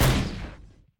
magic_magicmissle_cast.ogg